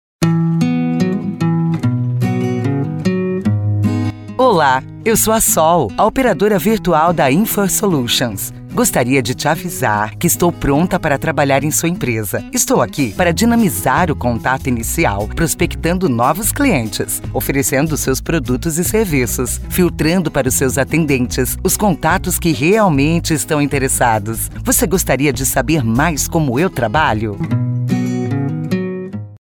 Que tal um áudio feminino para sua empresa?